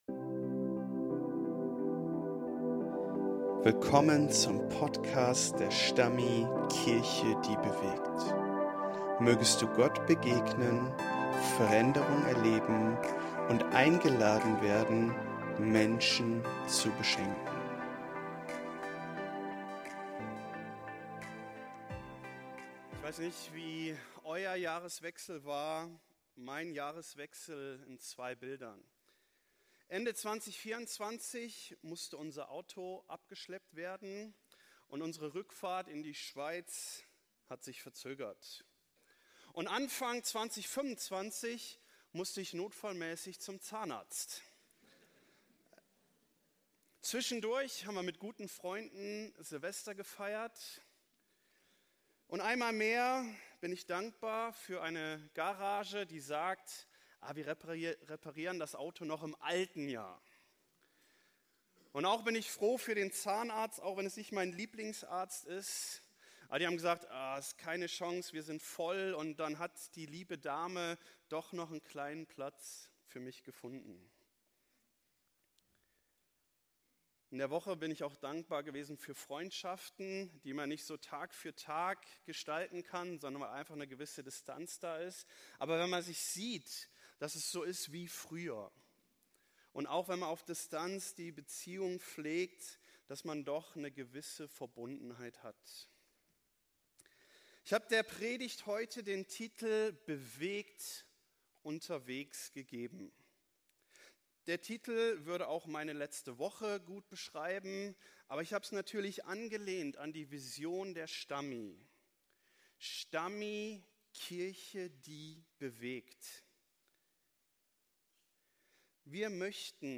Gottesdienst zum Jahresstart